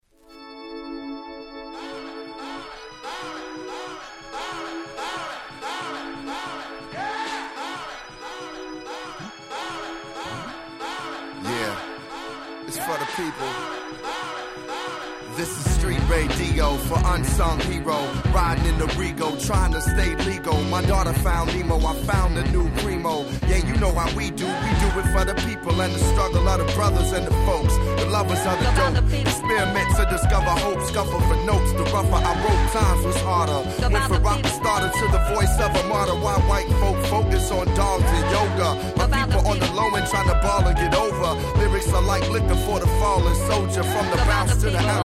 07' Hip Hop Classic !!